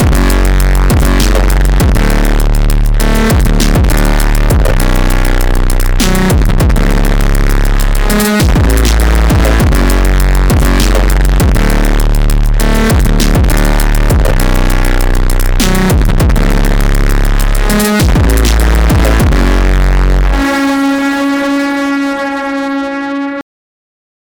Just used a lil trappy beat I had on my M:S, and made sure to carefully align everything so it was in phase.
I didn’t get the hardware plugin part set up so everything is recorded through my audio interface into Reaper from the pedal’s stereo output.
There might be a bit of 60hz hum in the distorted versions, but I haven’t taken the time to see if that’s dirty power or not, so it could just be how i’ve set everything up for tonight.
Heavy Distortion: